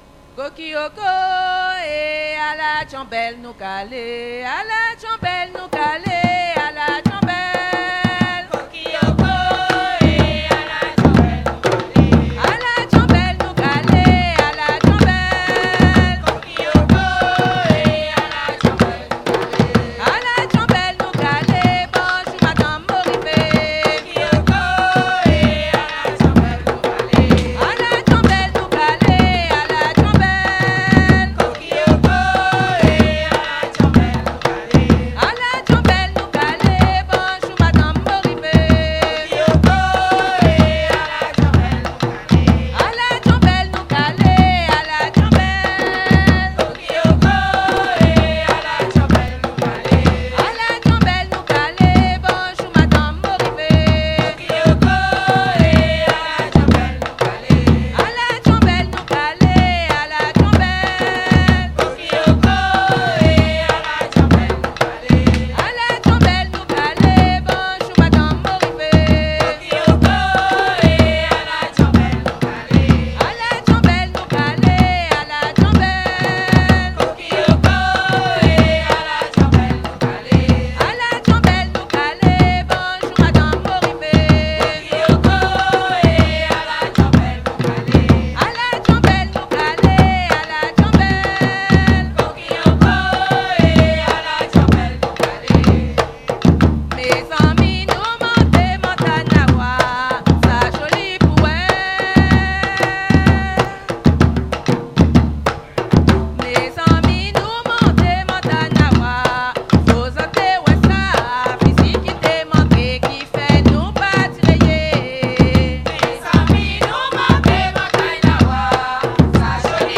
Soirée Mémorial
danse : djanbel (créole) ; danse : grajévals (créole)
Pièce musicale inédite